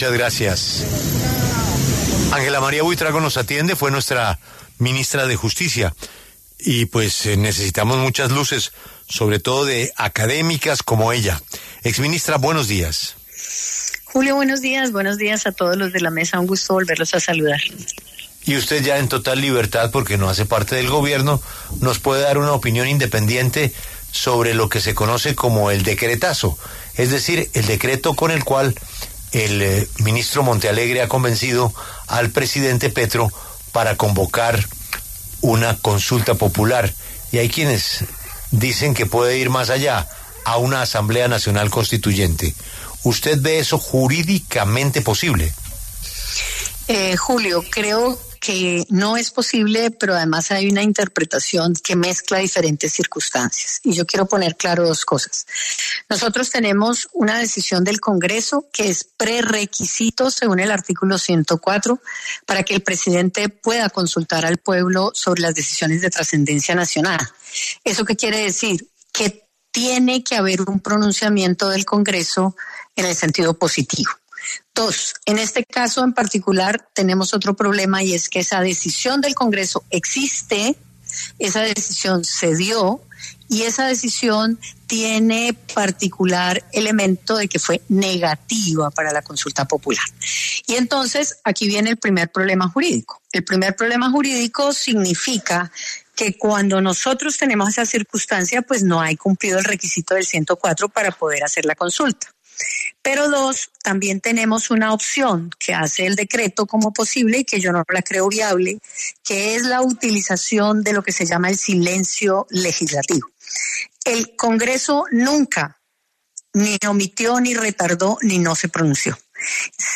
En entrevista con La W, la exministra de Justicia, Ángela María Buitrago, cuestionó duramente el decreto de consulta popular señalando que no existió irregularidad alguna en la votación de la consulta popular en el Senado y al contrario “esa decisión existe, se dio, y tiene particular elemento que fue negativo”.